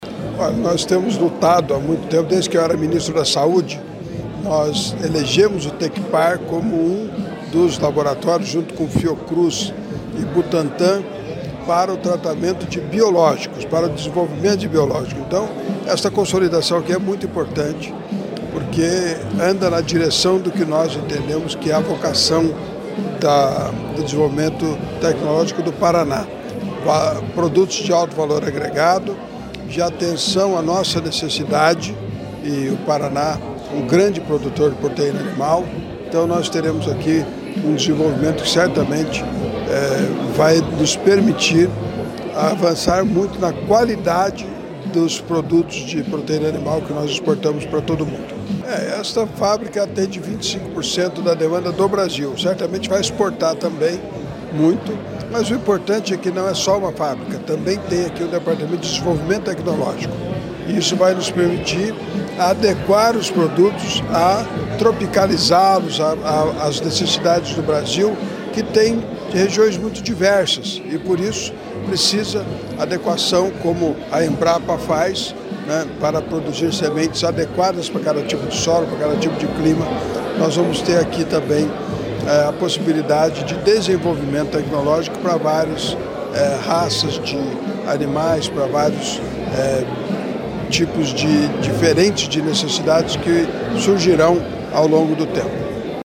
Sonora do secretário estadual da Indústria, Comércio e Serviços, Ricardo Barros, sobre o investimento de R$ 100 milhões em indústria veterinária em Campo Largo